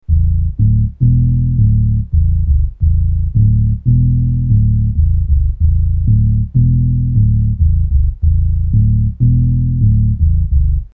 No effects were used and the mixer tone controls were set in neutral positions.
A low bass sound (unfortunately includes some hiss due to poor setting of record levels and subsequent attempts at compensation)
low_bass.mp3